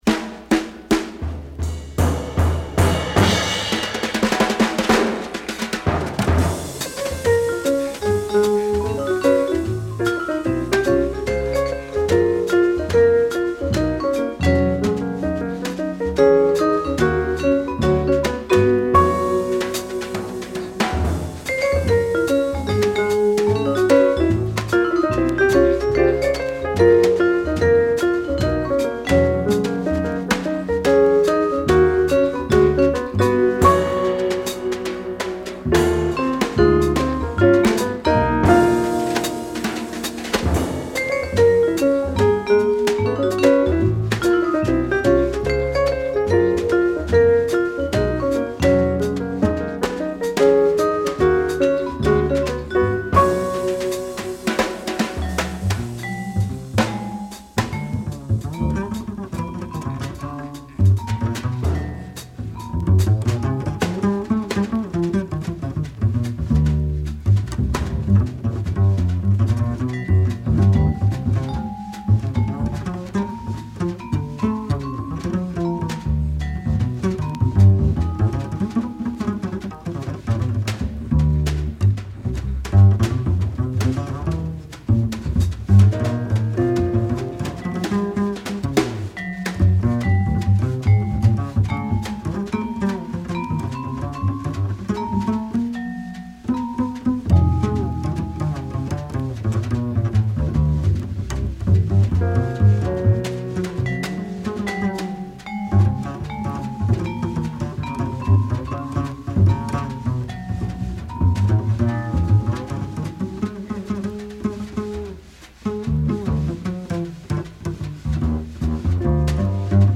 stereo reissue